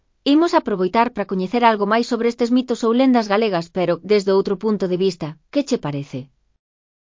Lectura facilitada
Elaboración propia (proxecto cREAgal) con apoio de IA voz sintética xerada co modelo Celtia.